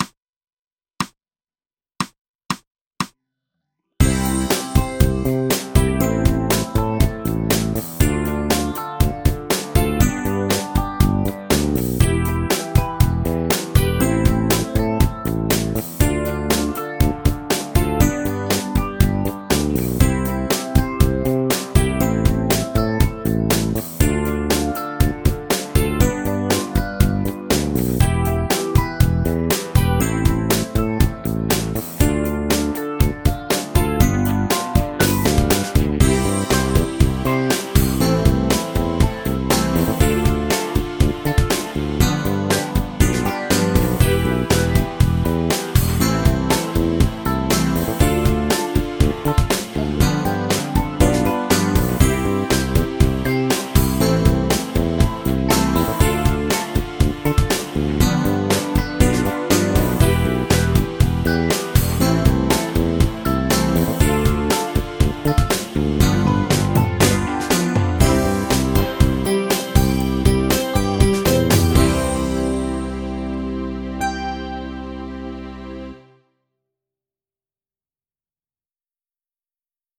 メジャー・スケール ギタースケールハンドブック -島村楽器